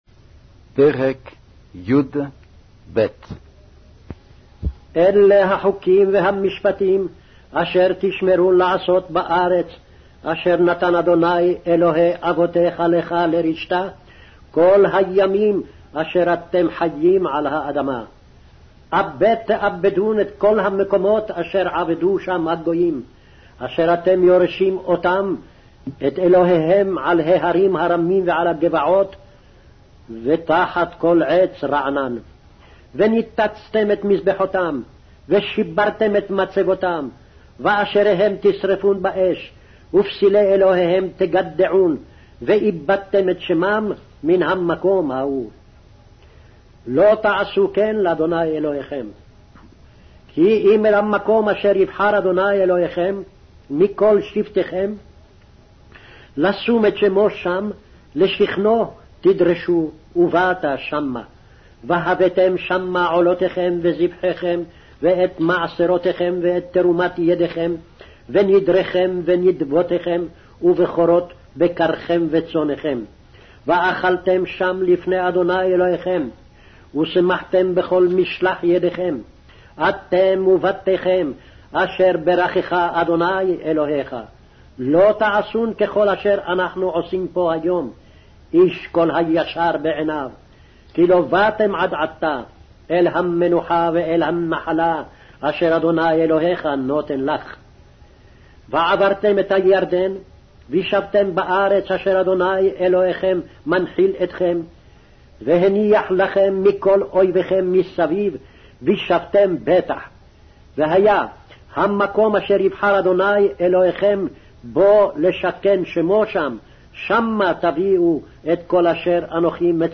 Hebrew Audio Bible - Deuteronomy 1 in Irvpa bible version